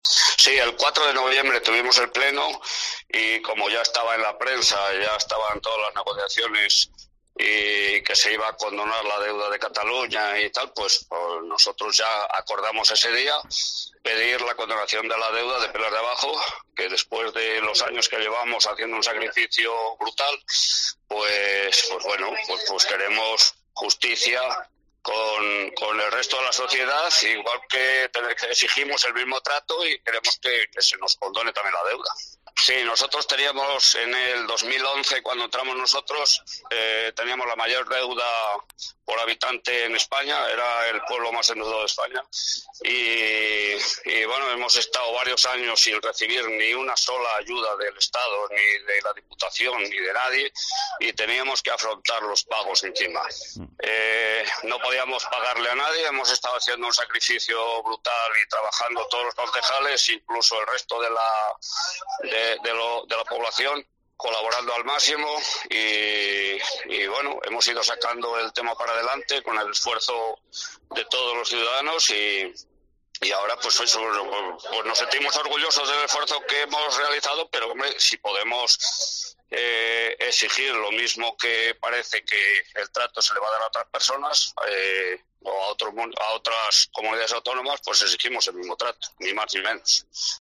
Félix Roncero, alcalde de Peleas de Abajo en Zamora